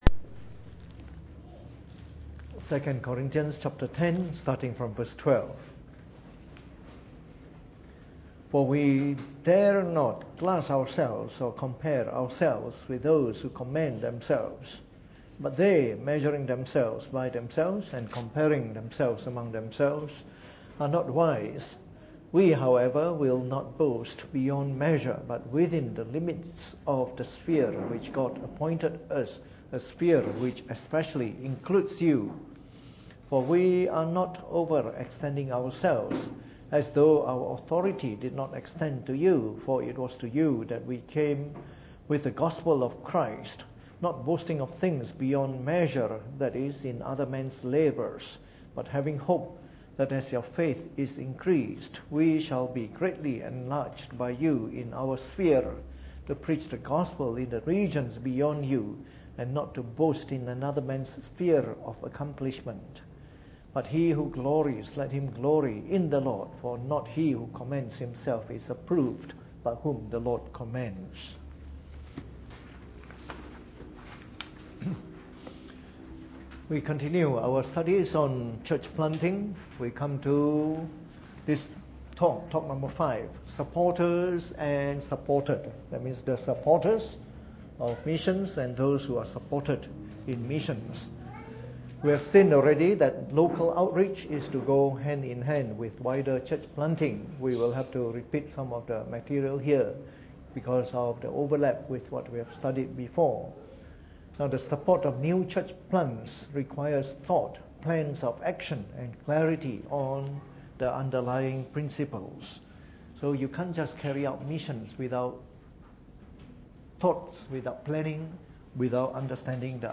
Preached on the 26th of July 2017 during the Bible Study, from our series on Church Planting Today.